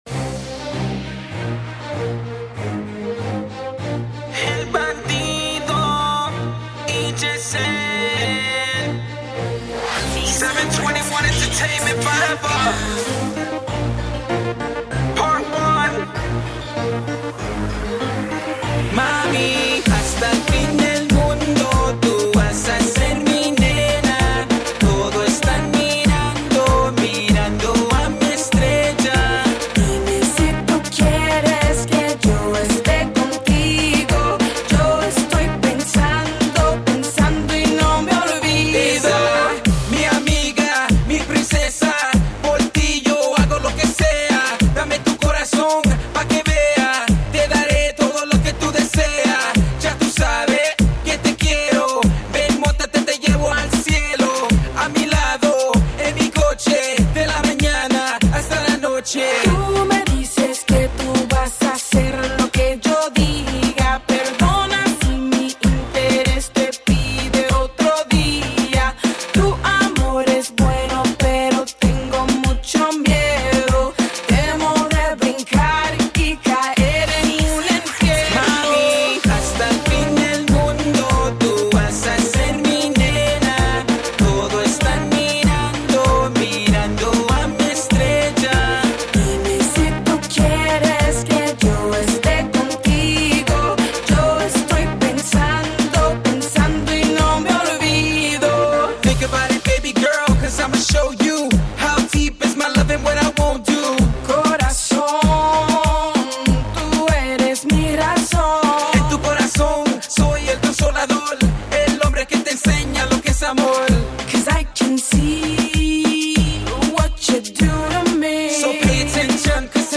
Bringing a new sound and style to Reggaeton.
Other Vocalists